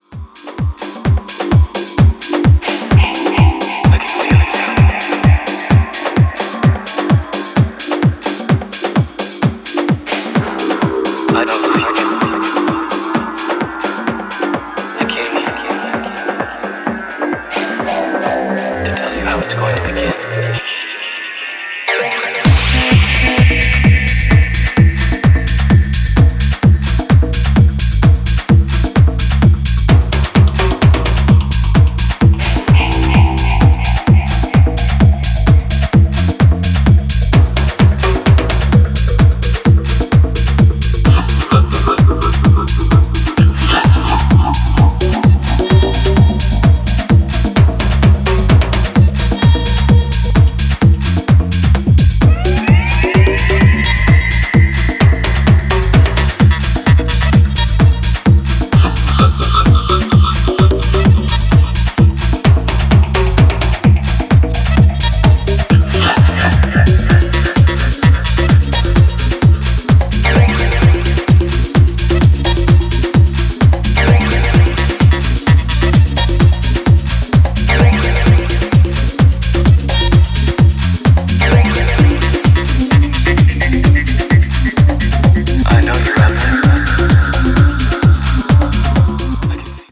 UKGからの影響も感じられるベースラインの圧の強さが新質感のモダン・ディープ・ハウスを展開